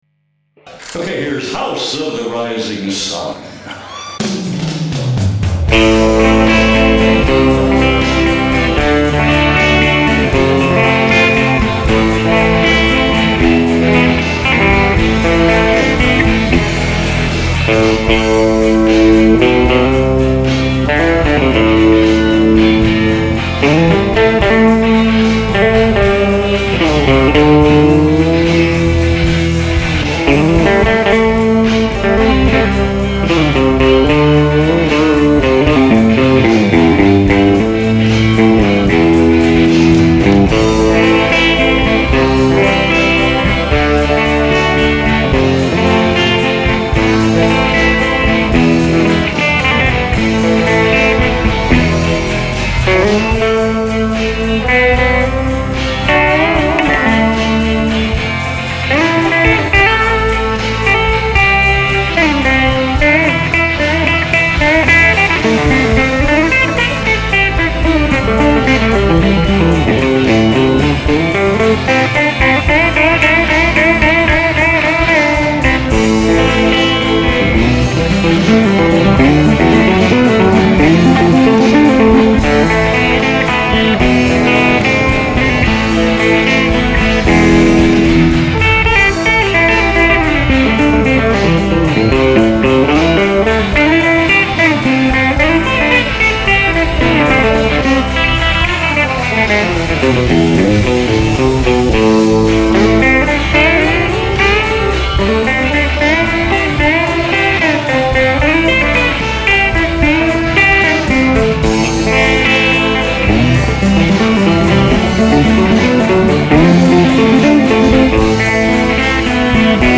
最初の印象が、なんか懐かしい音だなというもので、ソリッドギターにはない温かみのある音です。
…と、思って取り急ぎ７０曲ばかり演奏してから再生してみたら、録音レベルがオーバーしていて音がひずんでいました。
これらの録音はBOSSのＪＳ−１０という装置で、エフェクター内蔵のかなり優れものです。